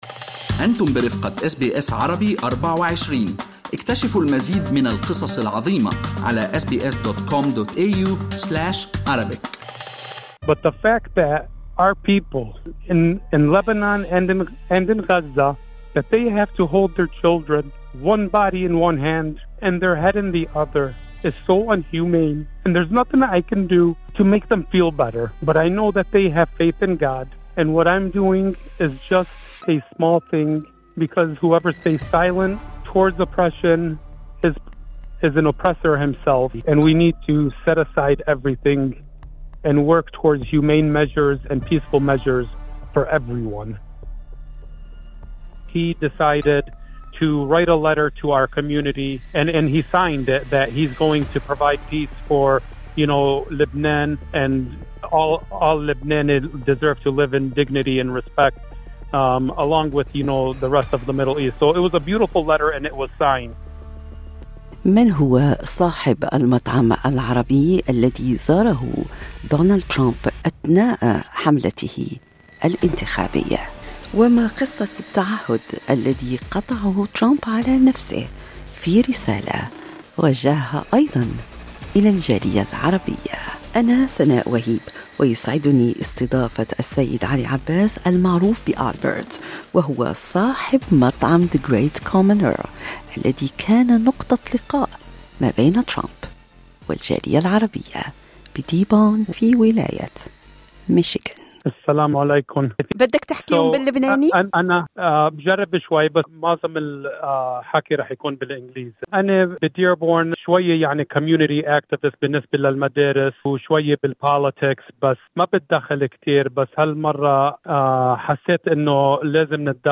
في لقاء مع أس بي أس عربي